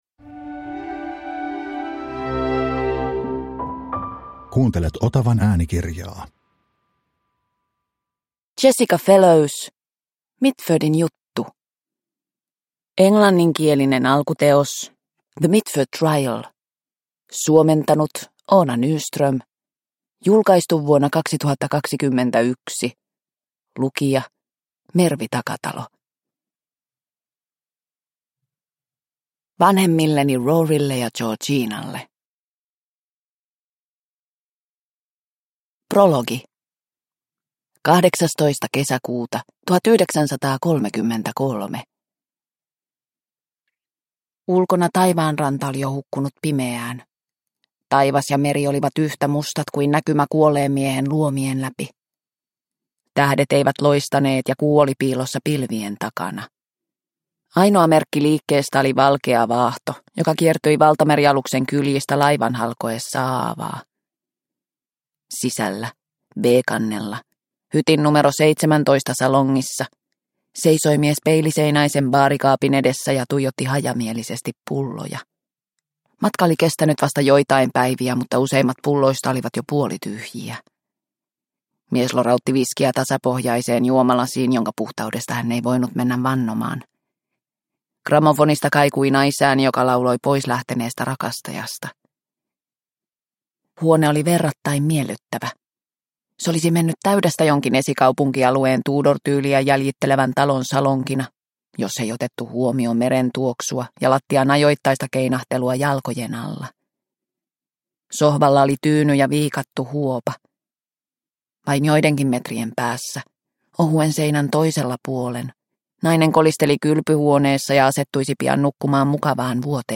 Mitfordin juttu – Ljudbok – Laddas ner